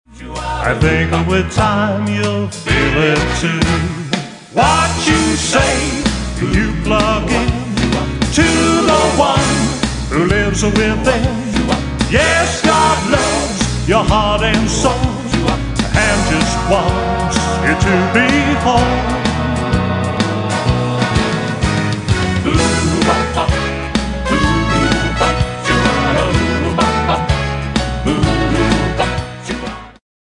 background vocals